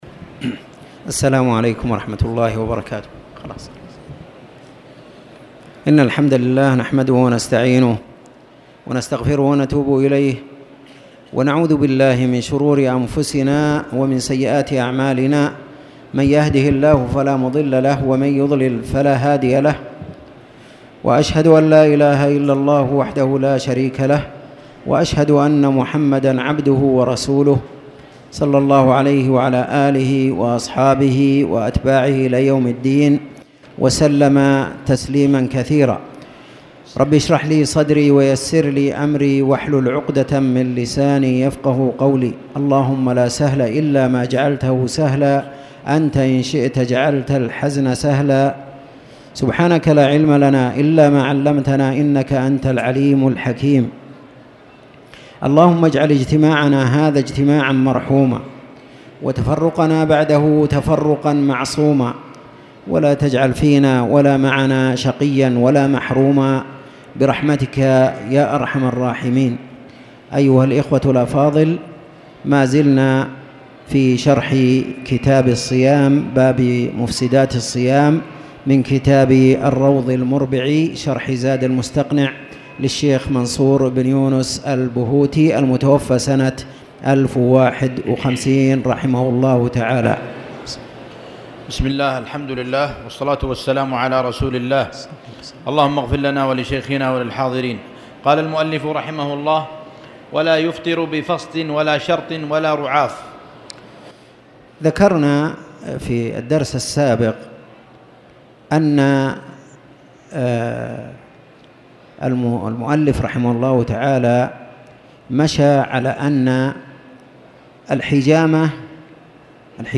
تاريخ النشر ٢٨ ربيع الأول ١٤٣٨ هـ المكان: المسجد الحرام الشيخ